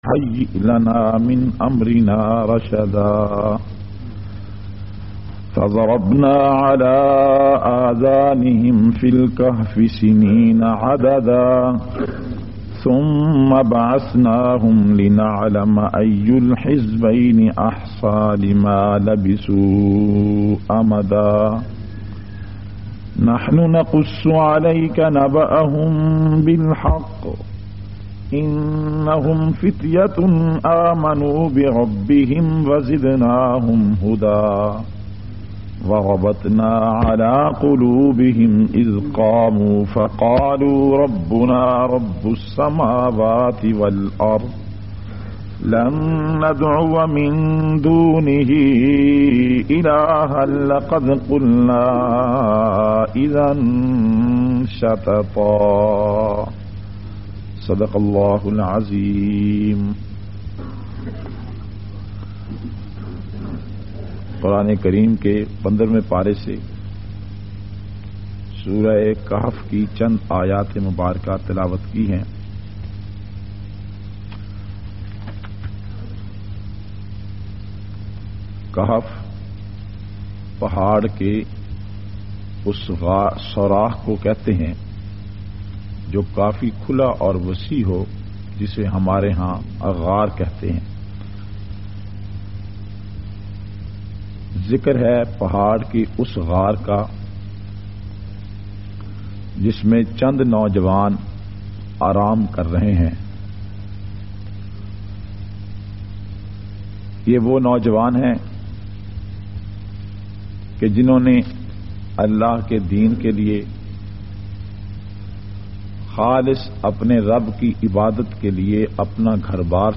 735- Dars e Quran-Sura Kahaf.mp3